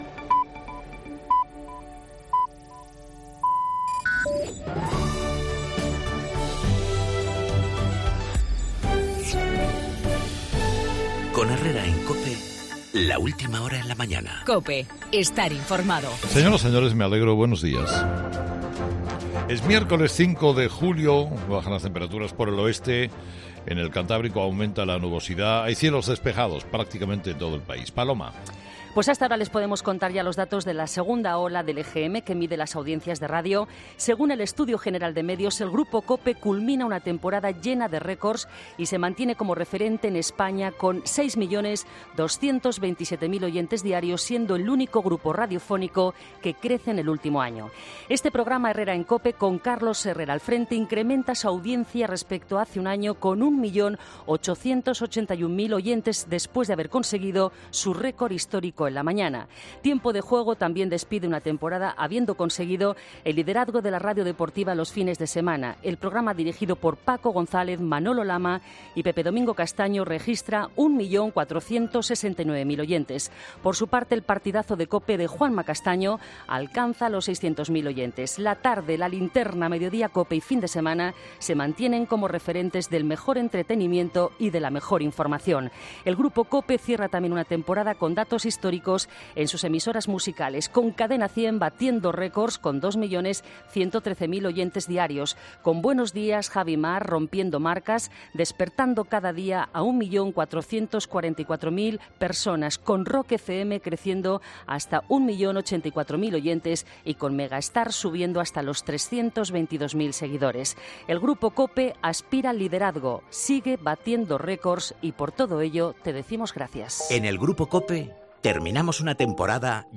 AUDIO: El nuevo capítulo del desafío independentista catalán, en el monólogo de Carlos Herrera a las 8 de la mañana.